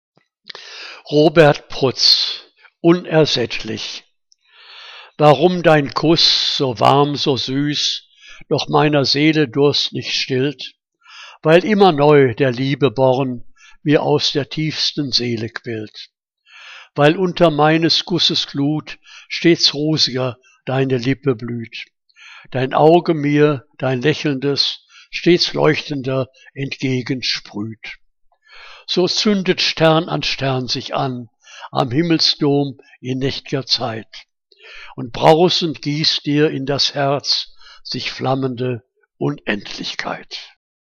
Liebeslyrik deutscher Dichter und Dichterinnen - gesprochen (Robert Prutz)